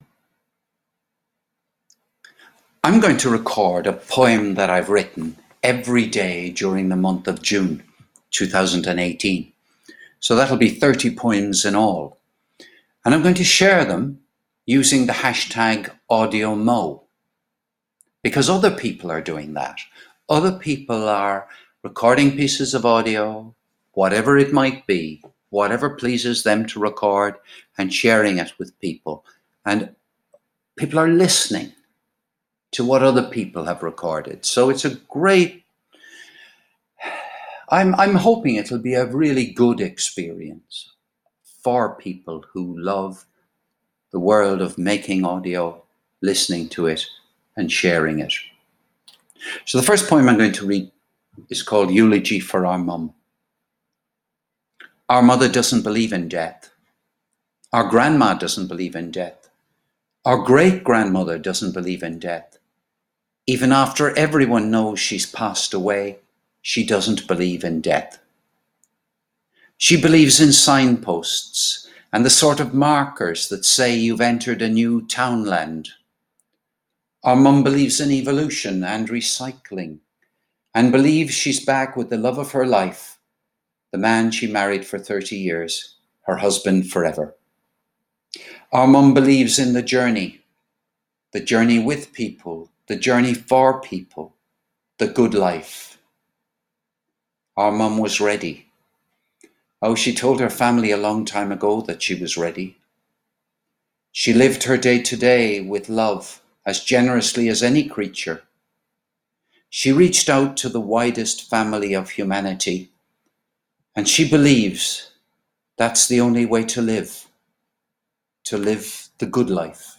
A poem